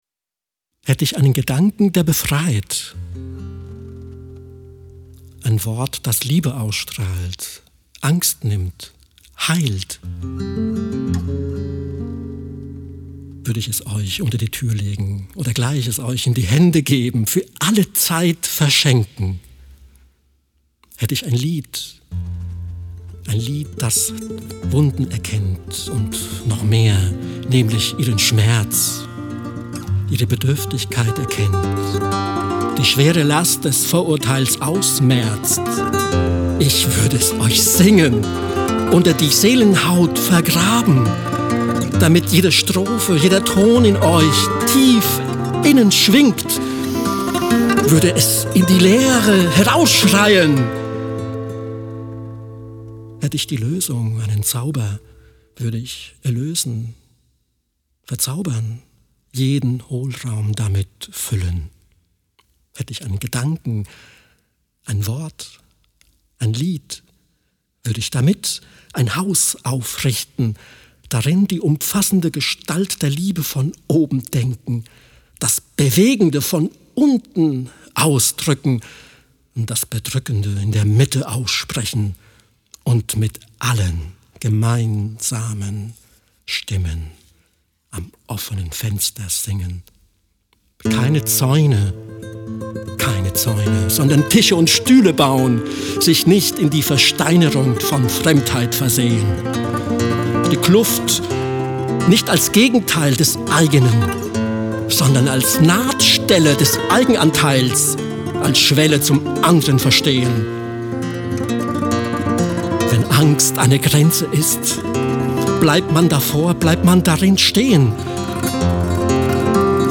Hörbuch
Hätte ich einen Gedanken, der befreit… Poesie & Spanische Gitarre